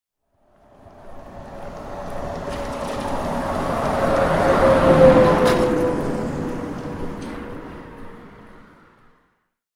Звук проводов при прохождении троллейбусом стрелки